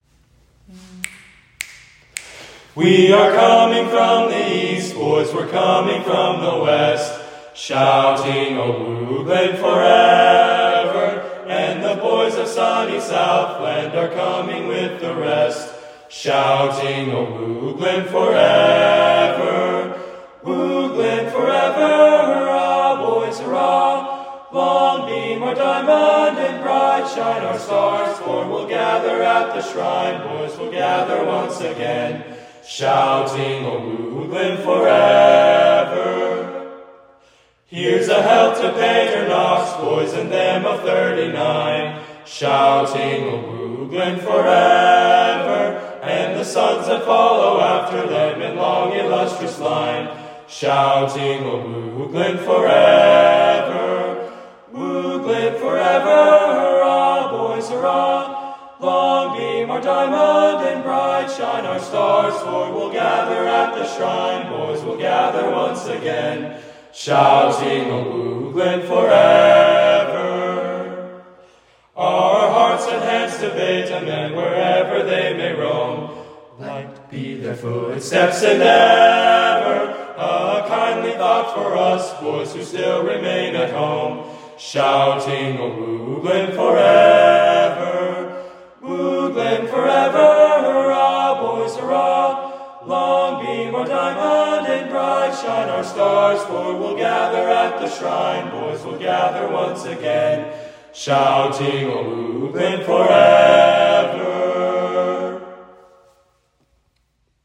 Singing Awards